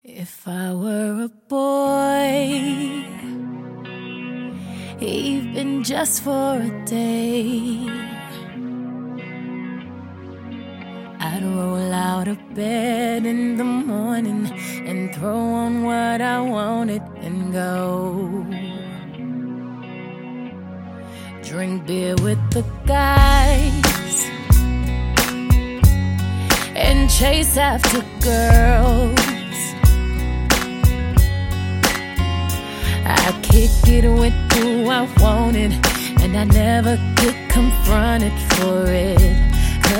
поп
rnb